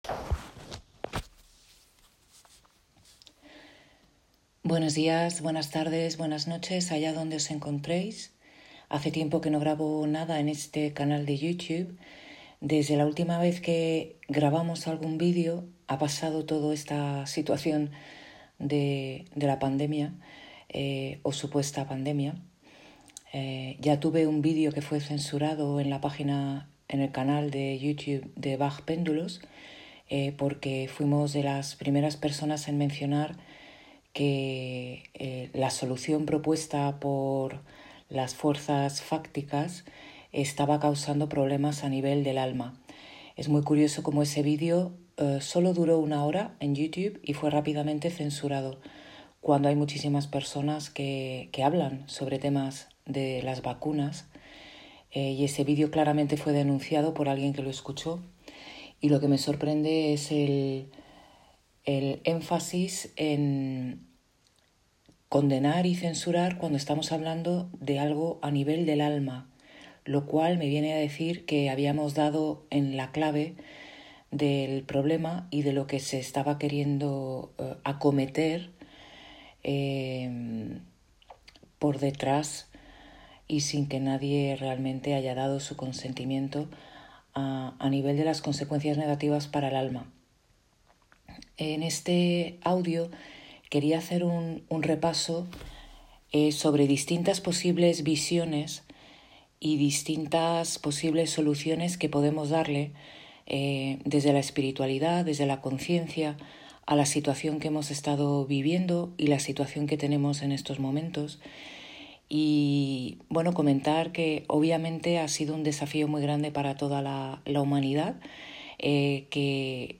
Un audio meditativo para contemplar desde la Visión Absoluta y Relativa nuestra situación actual